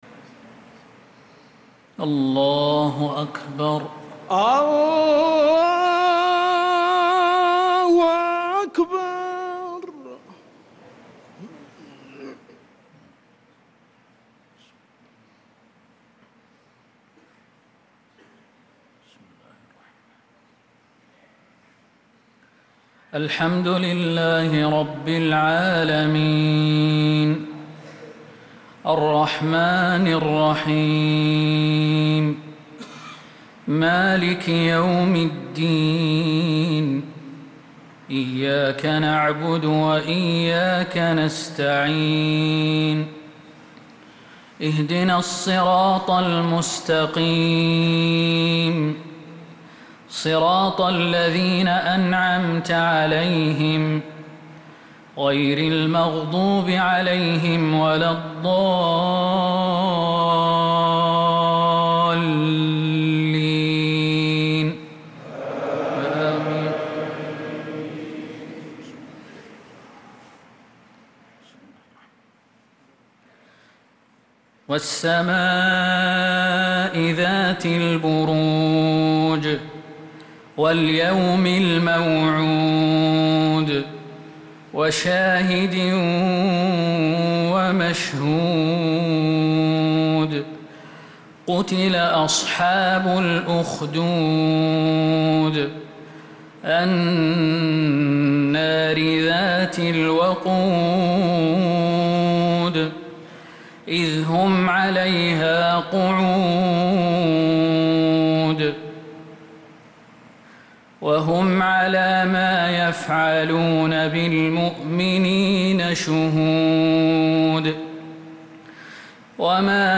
صلاة العشاء للقارئ خالد المهنا 11 ربيع الآخر 1446 هـ
تِلَاوَات الْحَرَمَيْن .